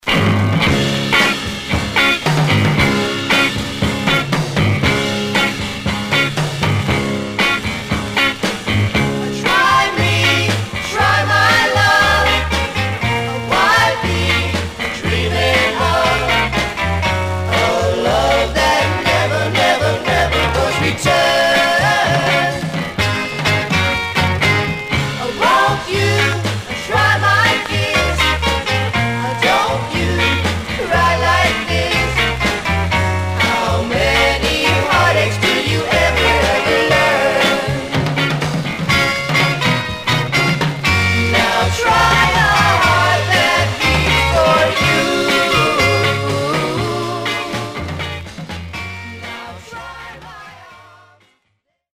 Surface noise/wear Stereo/mono Mono
White Teen Girl Groups